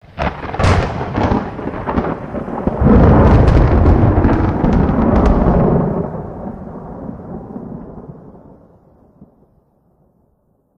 thundernew5.ogg